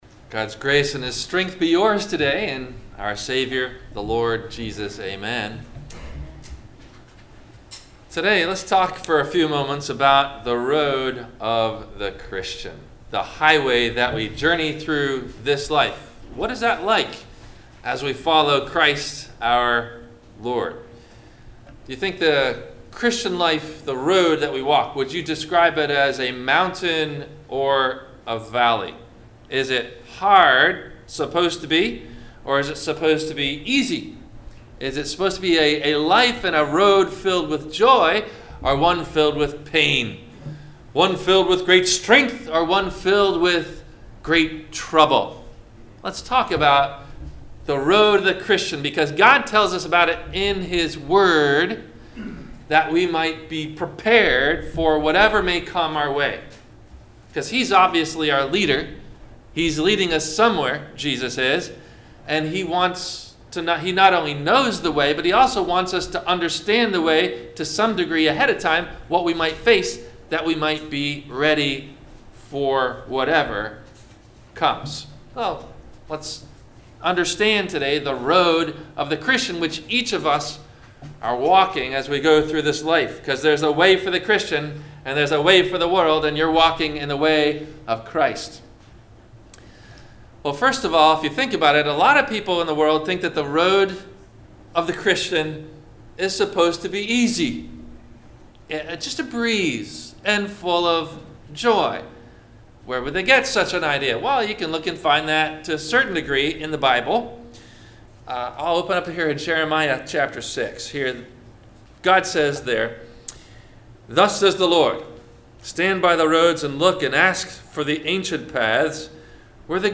- Sermon - August 04 2019 - Christ Lutheran Cape Canaveral